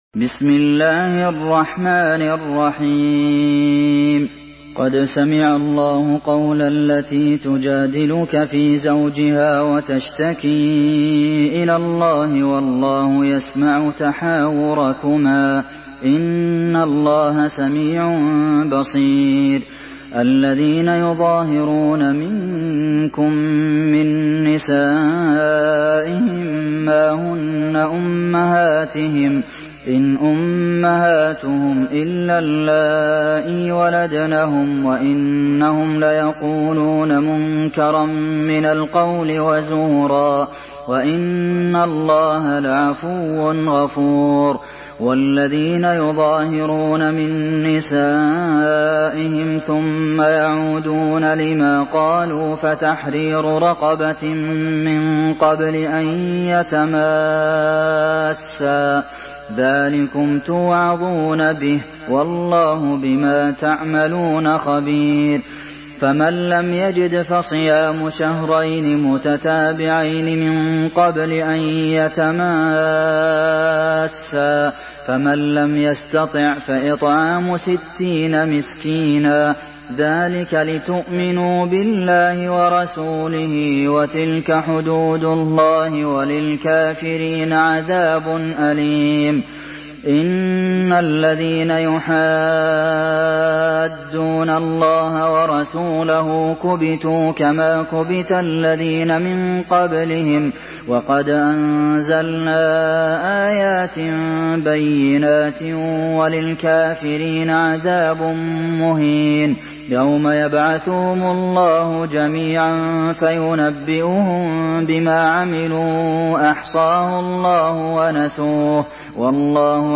سورة المجادلة MP3 بصوت عبد المحسن القاسم برواية حفص عن عاصم، استمع وحمّل التلاوة كاملة بصيغة MP3 عبر روابط مباشرة وسريعة على الجوال، مع إمكانية التحميل بجودات متعددة.
مرتل حفص عن عاصم